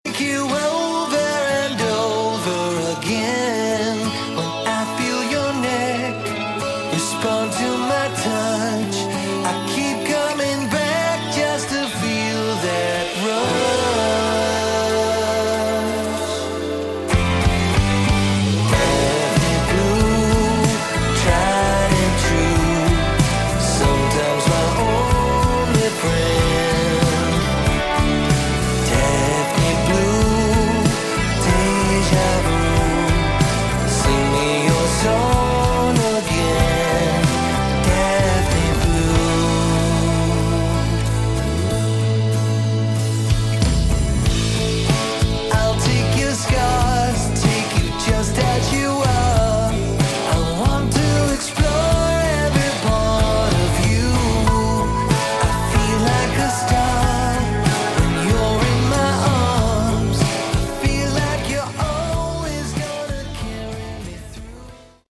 Category: Melodic Rock